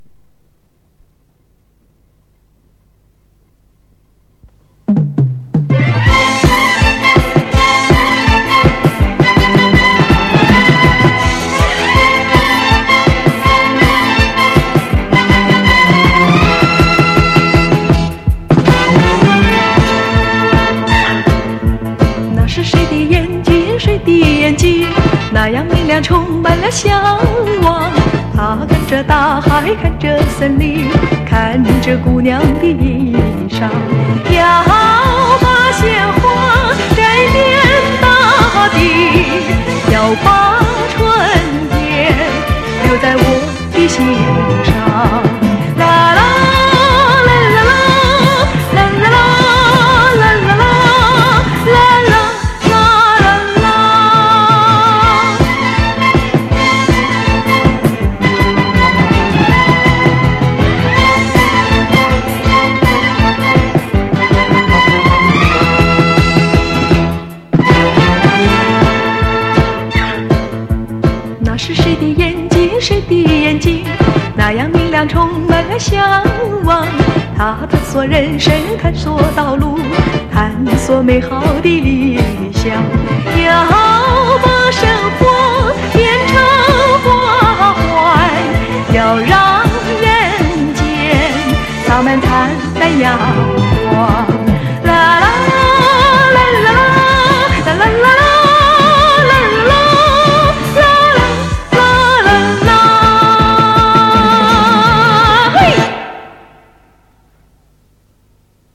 她的歌感觉平实、抒情、自然、真挚，在时代流行曲中融进西洋声乐的唱法，轻快中流露深情，朴实中迸发纯美。